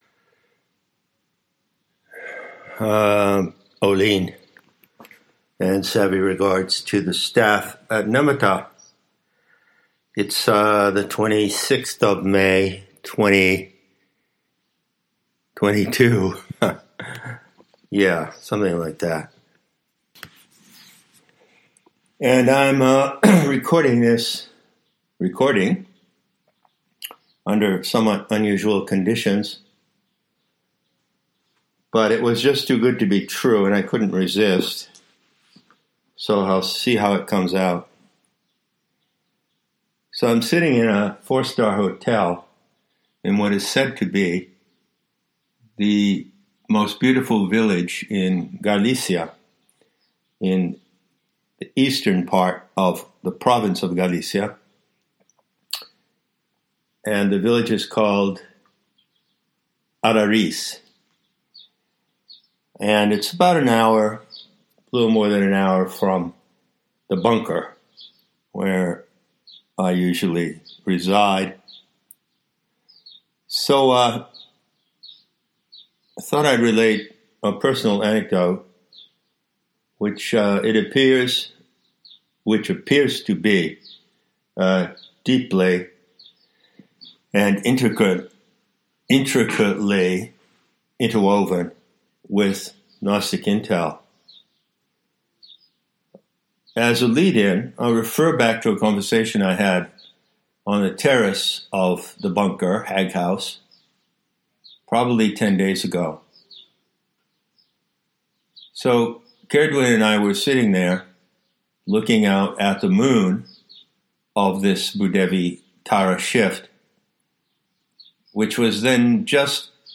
To introduce the new block in Course 2 Sophianic Cosmology, COSMIC PROJECTION, I am leading with a talk I recorded in Allariz, Galicia, Spain on May 26, 2022. This block will contain the units previously released in Dog Zen on cosmic projection, plus new content on the distinction of booth-side and theater-side, and the holographic analogue to the 36 Tattvas.